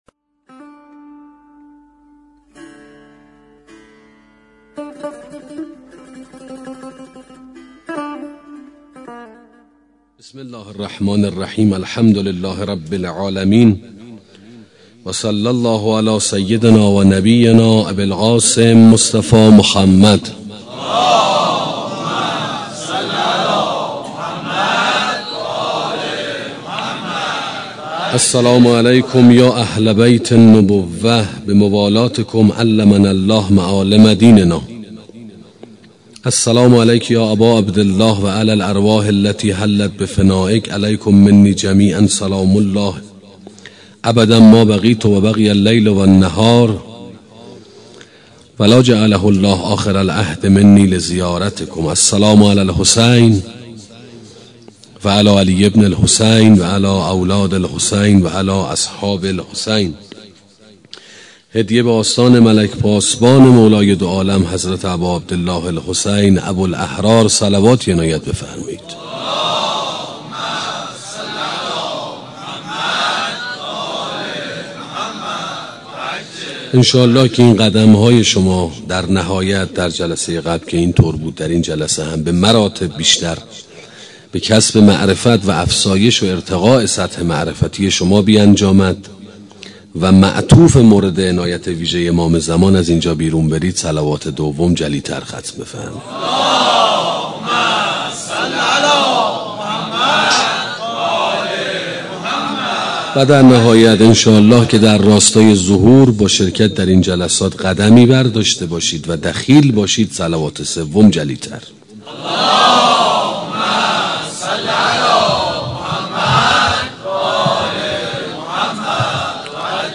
سخنرانی معرفت حسینی 2 - موسسه مودت